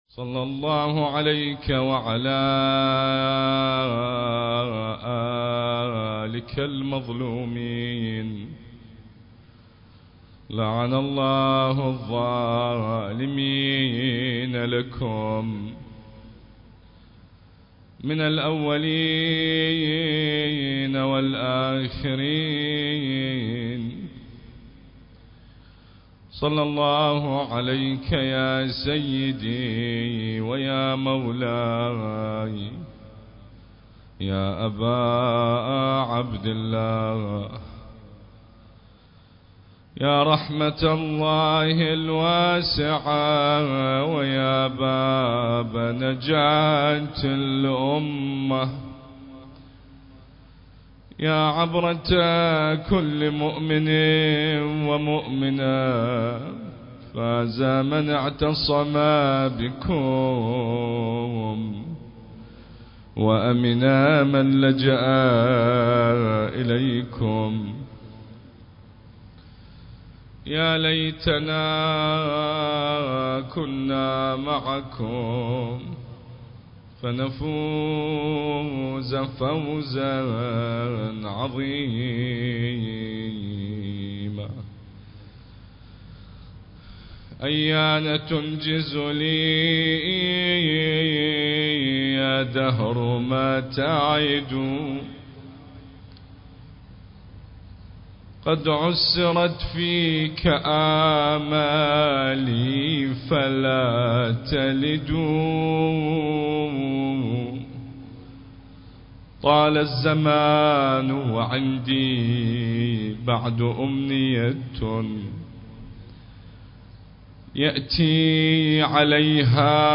المكان: حسينية المرحوم الحاج داود العاشور - البصرة